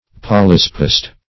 Search Result for " polyspast" : The Collaborative International Dictionary of English v.0.48: Polyspast \Pol"y*spast\, n. [L. polyspaston, fr. Gr.